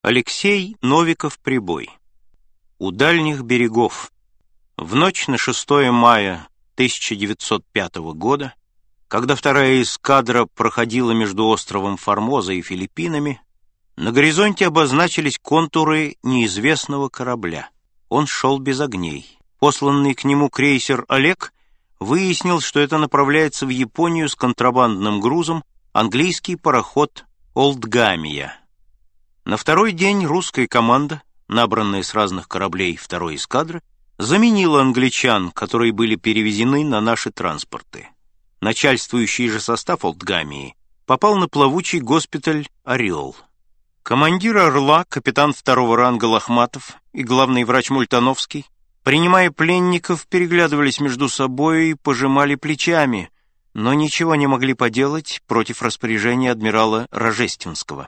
Аудиокнига У дальних берегов | Библиотека аудиокниг
Прослушать и бесплатно скачать фрагмент аудиокниги